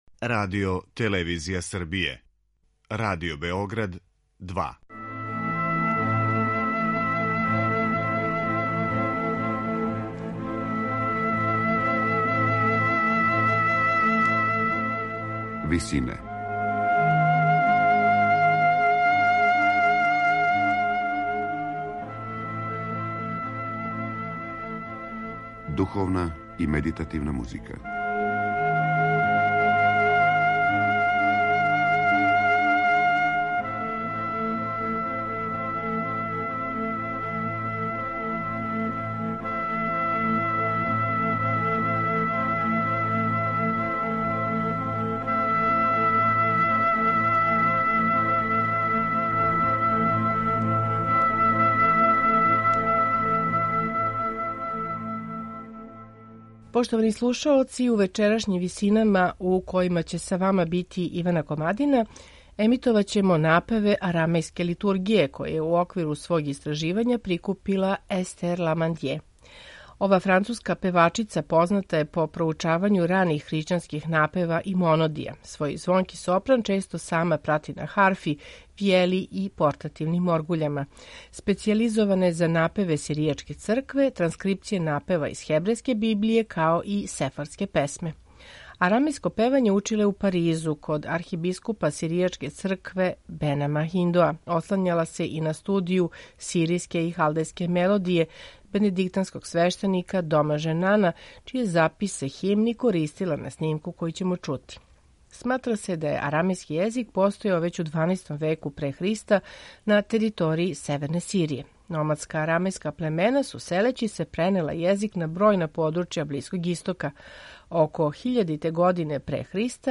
Сопран Естер Ламандје позната је по проучавању раних хришћанских напева и монодија.
Специјализована је за напеве сиријачке цркве, испеване на арамејском језику, транскрипције напева из хебрејске библије, као и сефардске песме. Она је 1990. године снимила молитве, похвале и химне које се изводе у сиријачим црквама у различитим приликама - од редовне службе, опела до великих празника попут Ускрса и Божића.